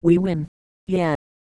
Worms speechbanks
victory.wav